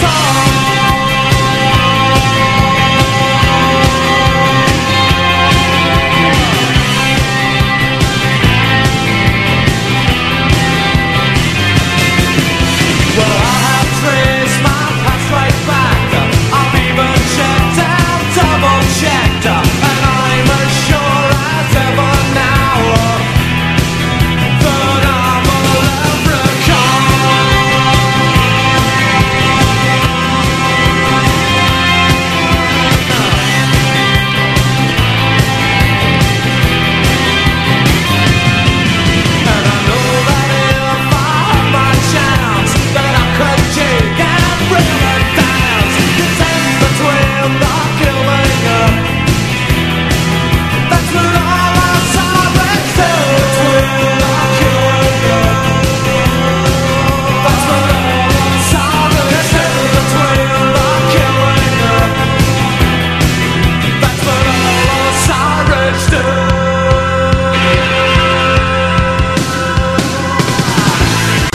PUNK / STREET PUNK / Oi!
POGO PUNK
90'Sポゴ・ダンシングな名曲・人気曲の連射！